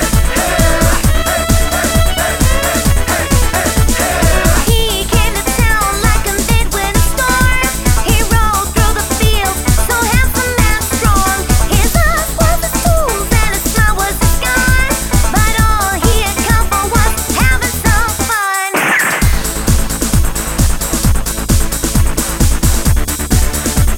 For Solo Male Duets 3:15 Buy £1.50